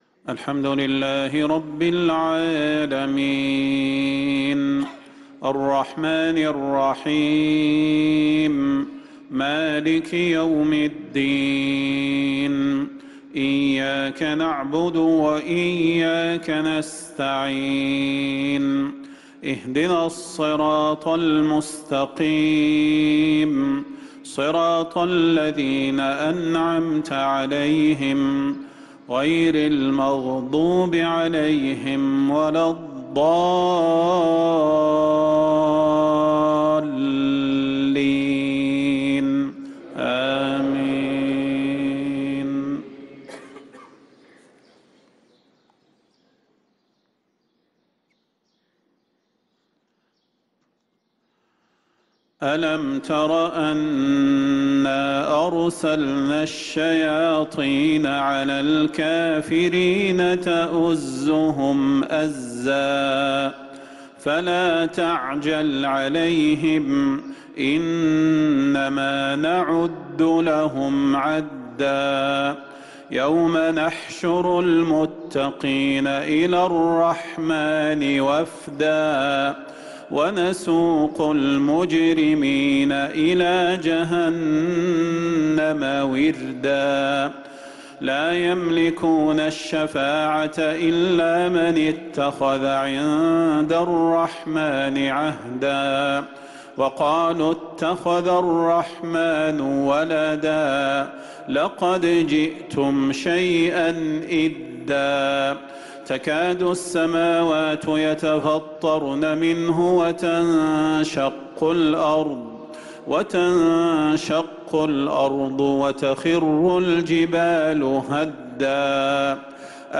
صلاة المغرب للقارئ صلاح البدير 6 شعبان 1445 هـ
تِلَاوَات الْحَرَمَيْن .